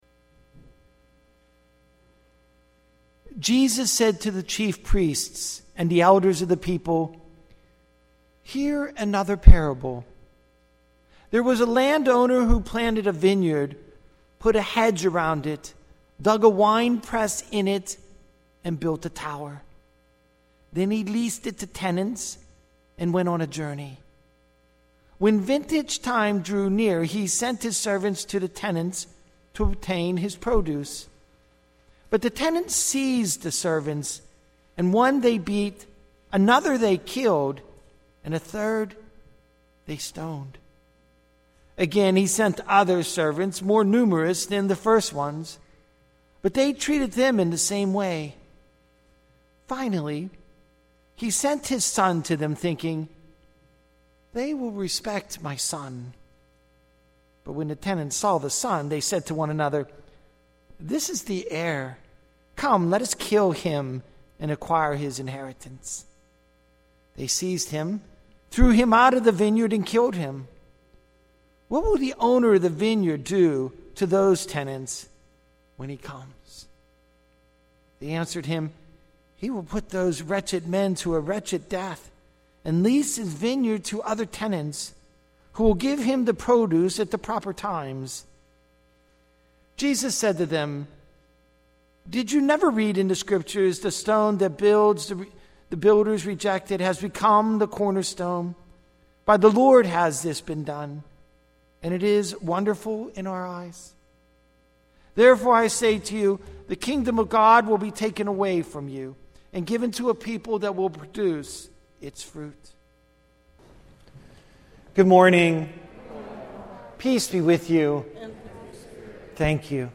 Weekly Homilies
Date: October 4, 2020 (Ordinary Time)